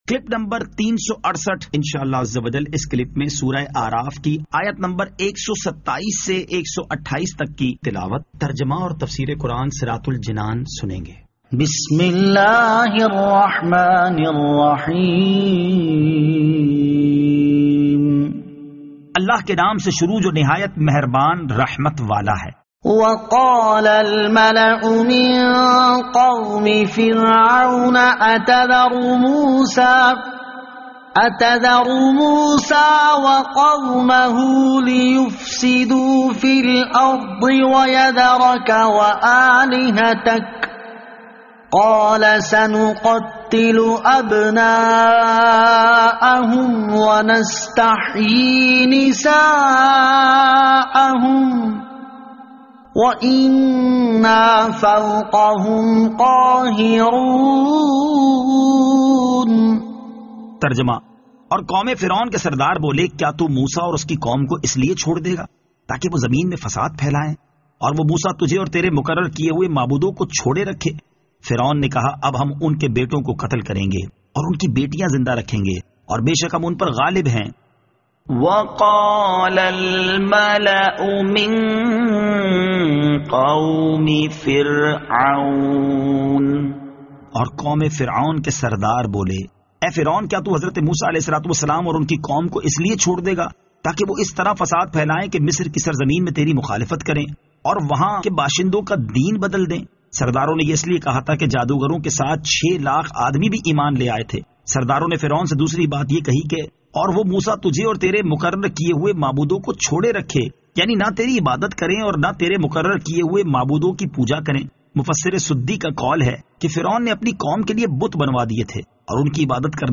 Surah Al-A'raf Ayat 127 To 128 Tilawat , Tarjama , Tafseer